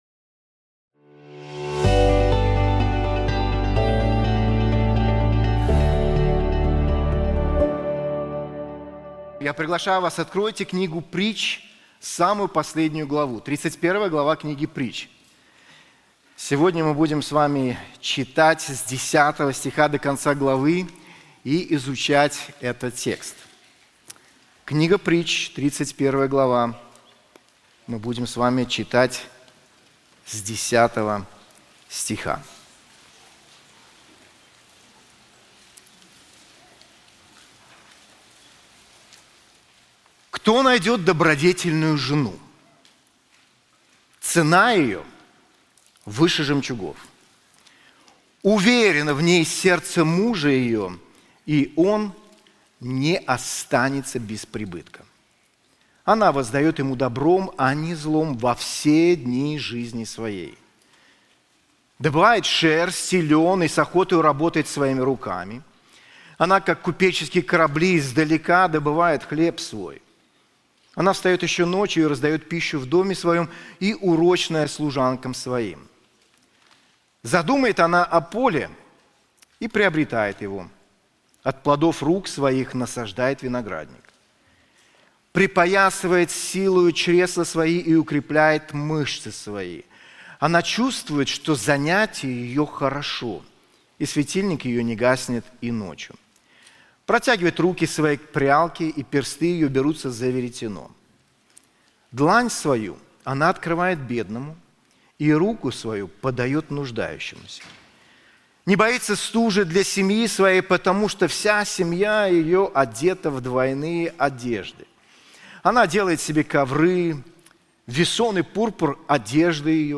This sermon is also available in English:Charm Is Deceitful, and Beauty Is Vain • Proverbs 31:10-31